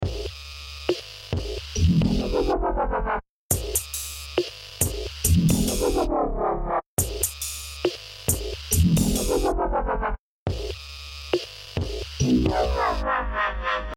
Tag: 138 bpm Dubstep Loops Drum Loops 2.34 MB wav Key : Unknown